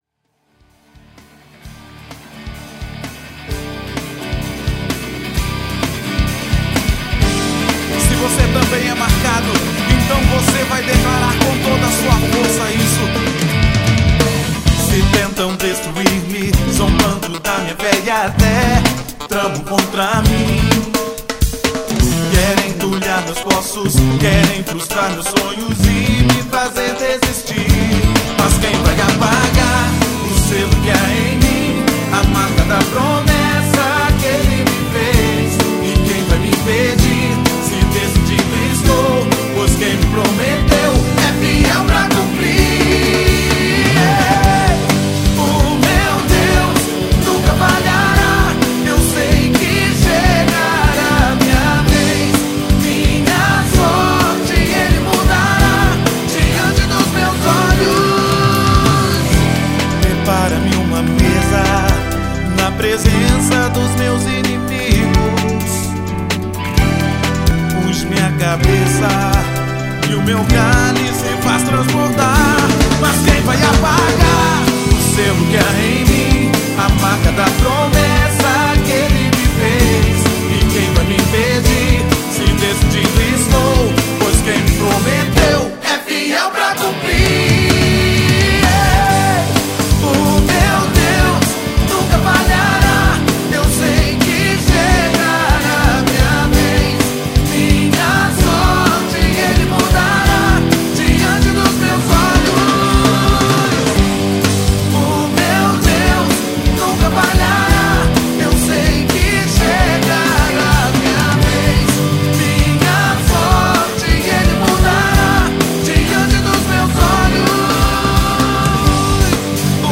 BPM129-129
Audio QualityLine Out